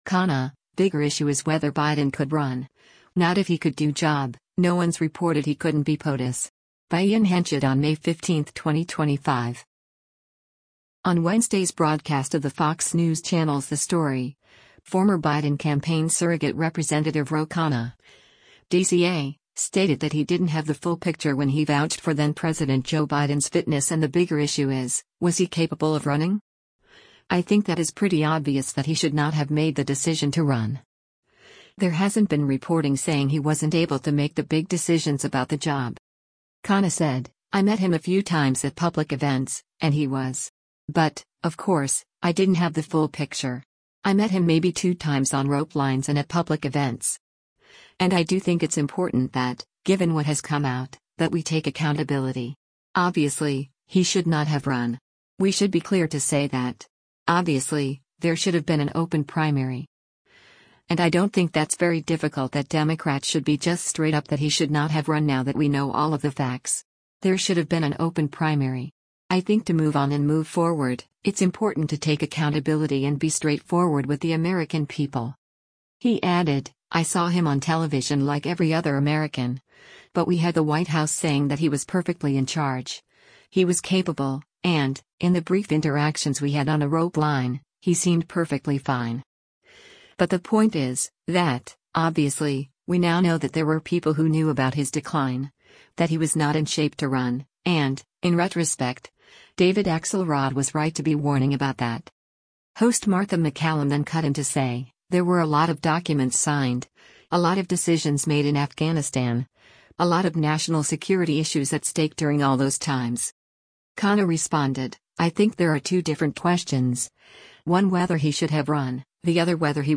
Host Martha MacCallum then cut in to say, “There were a lot of documents signed, a lot of decisions made in Afghanistan, a lot of national security issues at stake during all those times.”